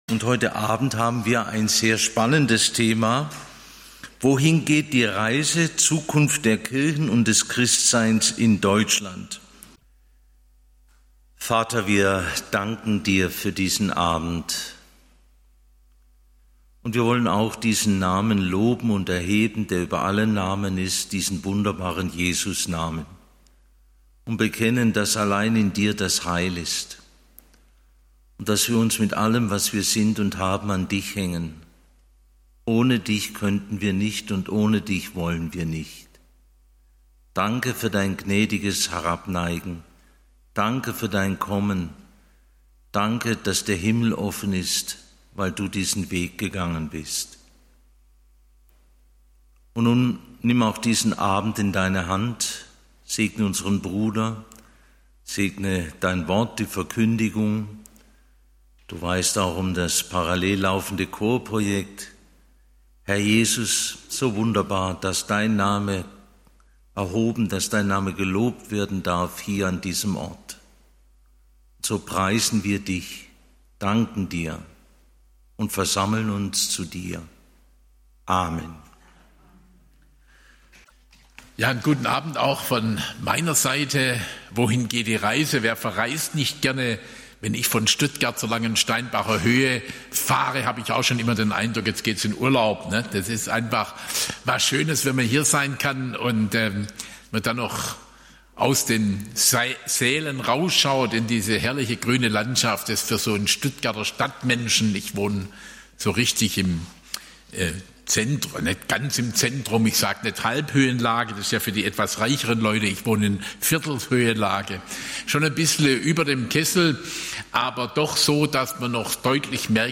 Das Internet und ich - Verantwortlich leben in einer digitalen Welt (Teil 1) - Bibelstunde